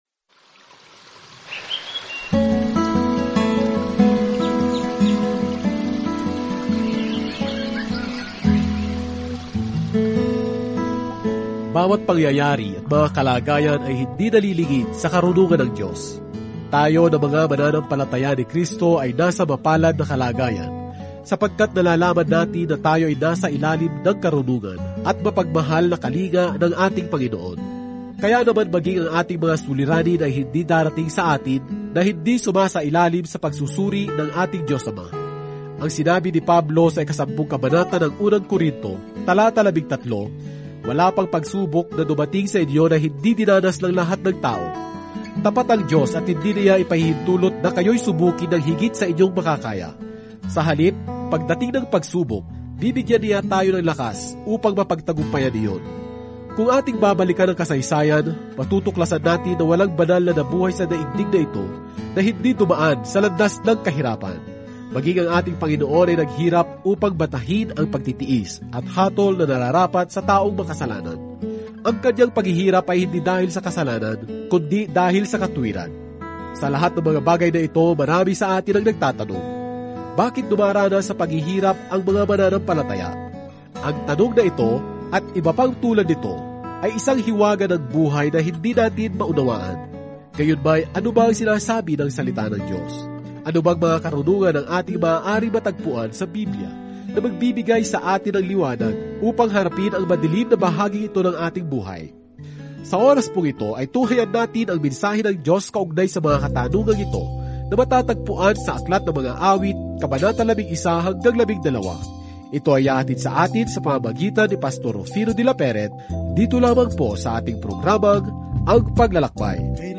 Banal na Kasulatan Mga Awit 11 Mga Awit 12 Araw 7 Umpisahan ang Gabay na Ito Araw 9 Tungkol sa Gabay na ito Ang Mga Awit ay nagbibigay sa atin ng mga kaisipan at damdamin ng isang hanay ng mga karanasan sa Diyos; malamang na ang bawat isa ay orihinal na nakatakda sa musika. Araw-araw na paglalakbay sa Mga Awit habang nakikinig ka sa audio study at nagbabasa ng mga piling talata mula sa salita ng Diyos.